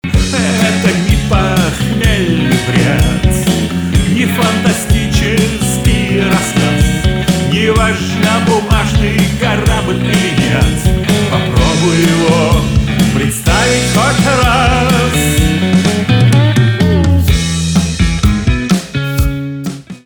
русский рок , гитара , барабаны